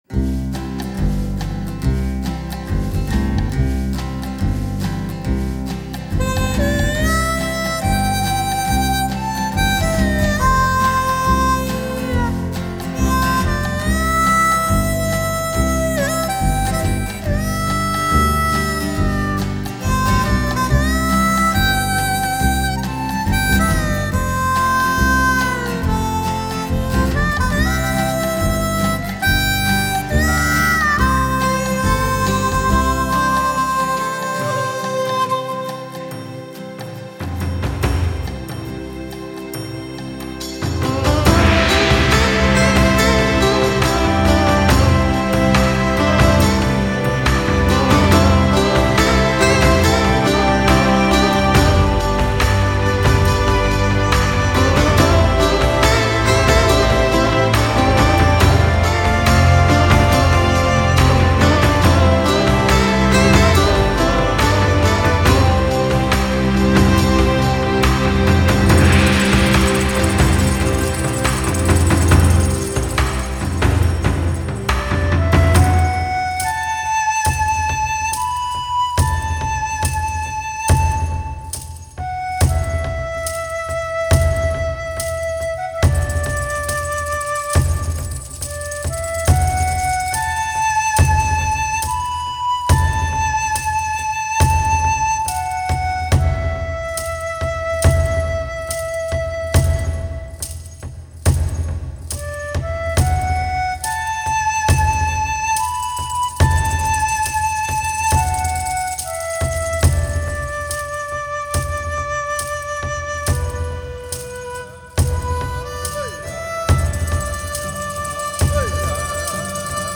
Ethnic
Combination